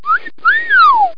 PFIFF.mp3